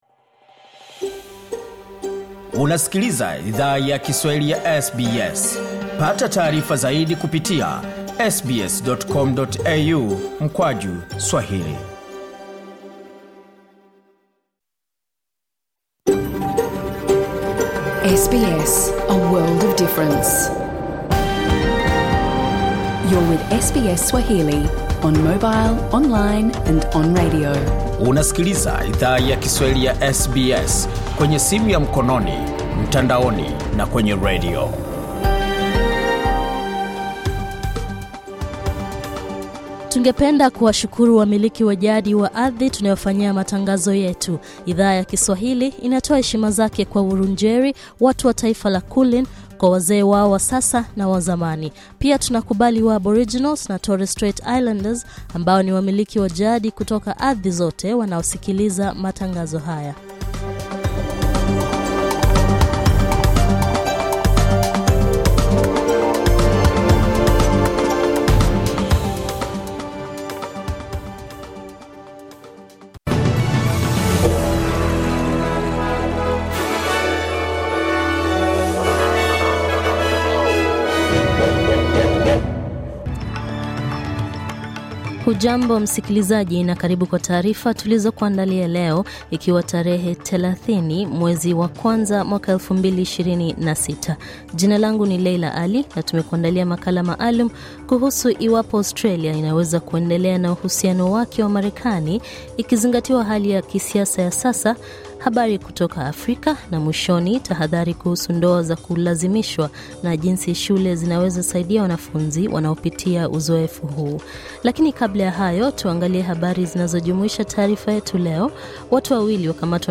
Taarifa ya habari:tahadhari ya jua na joto kali iliyovunja rekodi yaendelea kutolewa